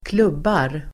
Uttal: [²kl'ub:ar]